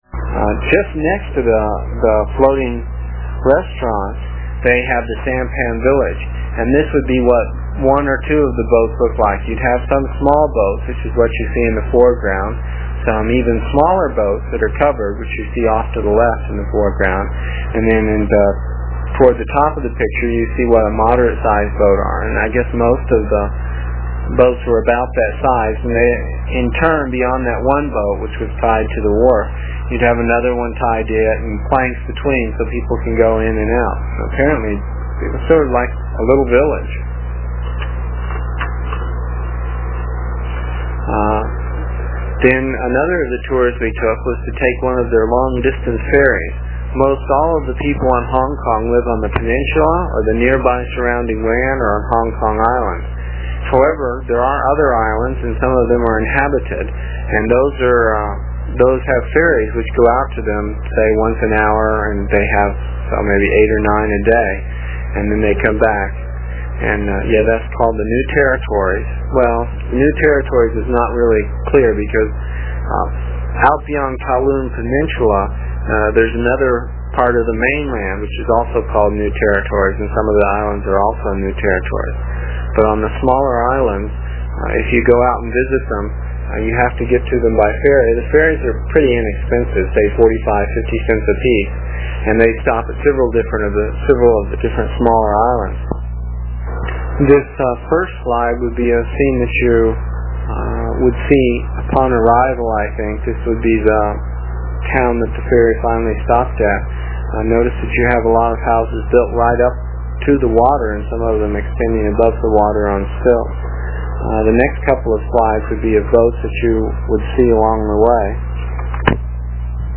It is from the cassette tapes we made almost thirty years ago. I was pretty long winded (no rehearsals or editting and tapes were cheap) and the section for this page is about six minutes and will take about two minutes to download with a dial up connection.